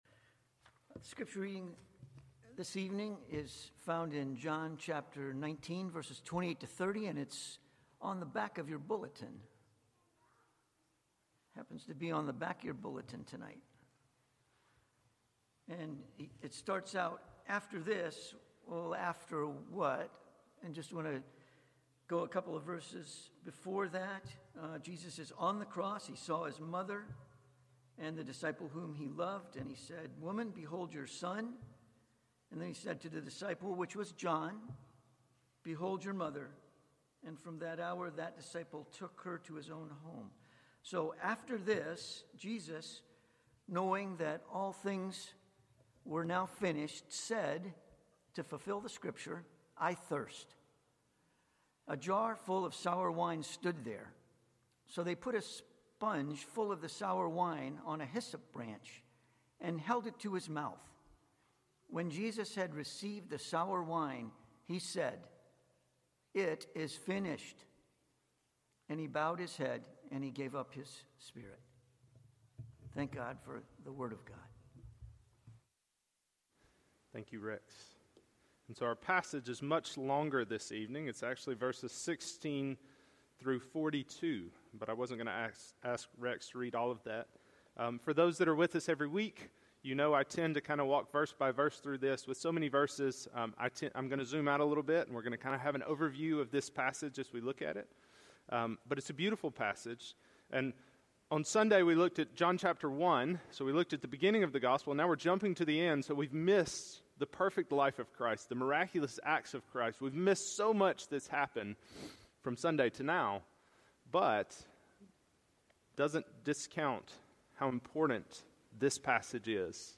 Passage: John 19:16-42 Sermon